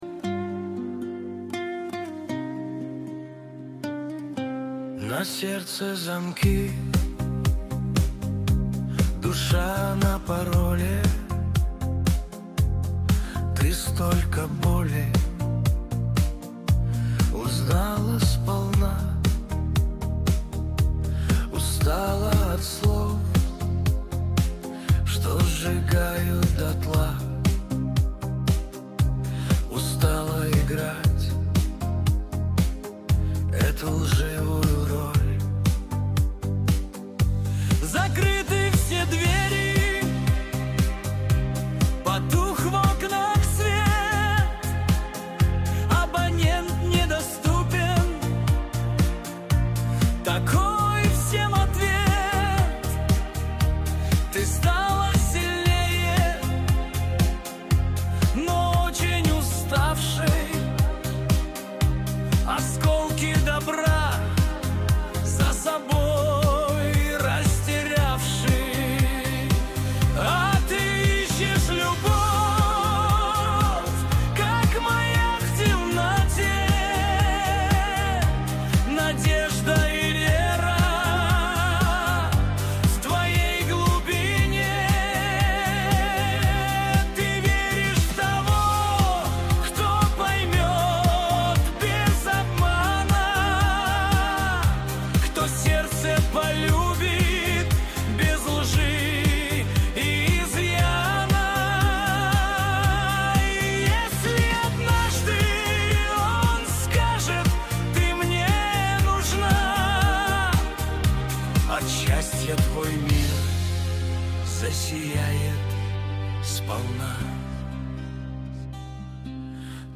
Качество: 128 kbps, stereo
Нейросеть Песни 2025, Поп музыка